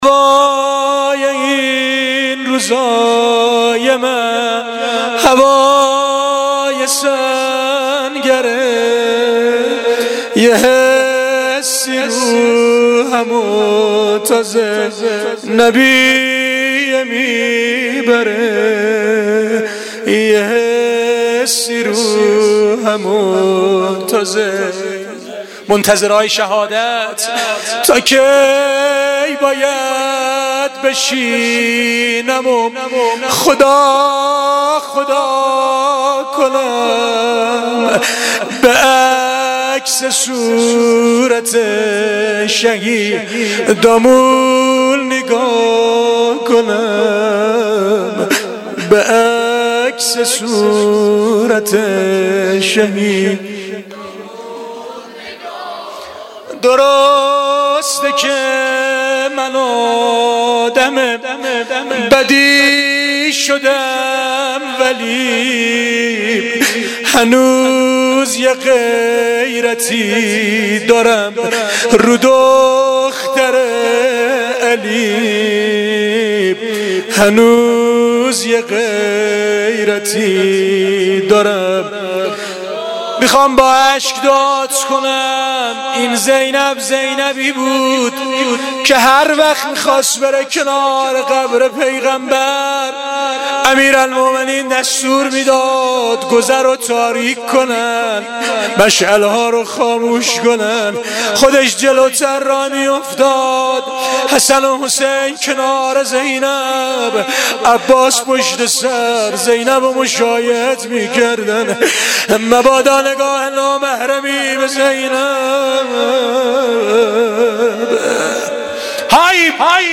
مناجات و روضه پایانی شب وفات حضرت خدیجه